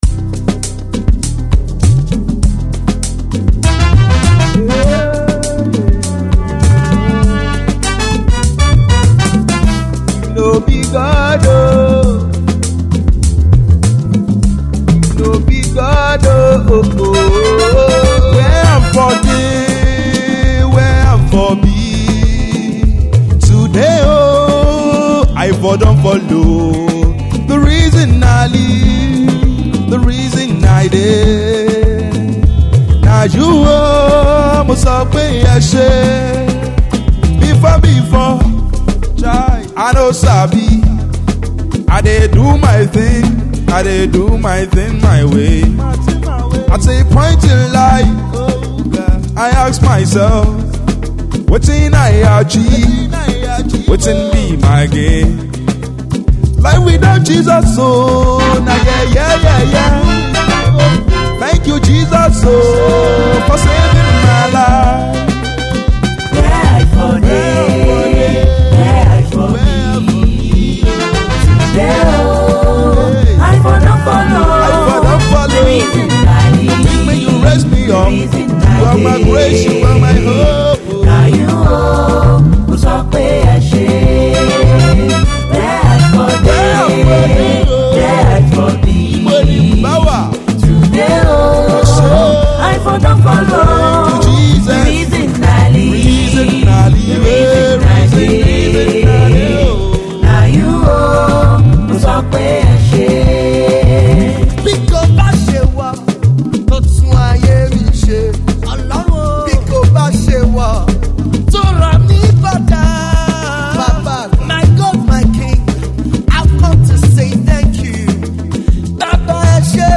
Gospel singer
lovely highlife mid-tempo single